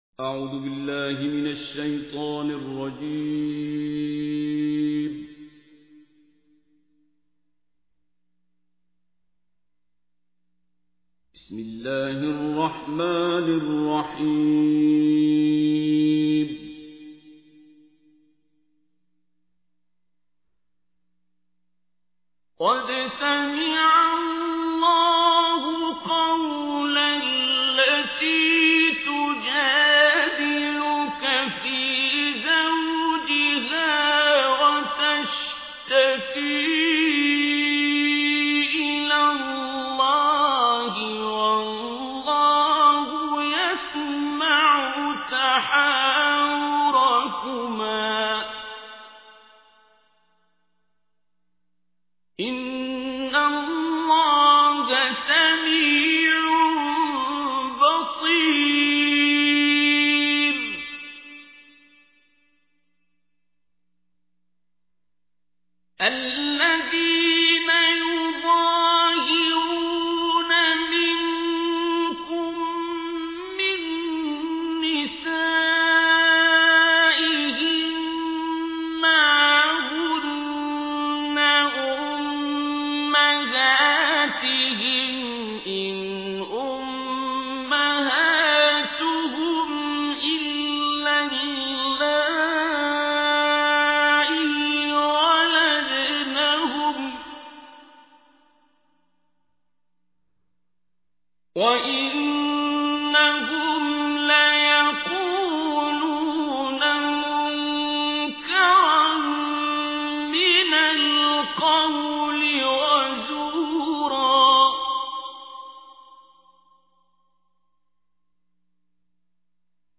تحميل : 58. سورة المجادلة / القارئ عبد الباسط عبد الصمد / القرآن الكريم / موقع يا حسين